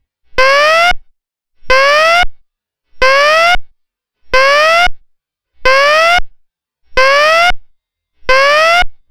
Provides up to 85 dB at 5 feet.
102/108 fast_whoop Wav File - 200.9K
fast_whoop.wav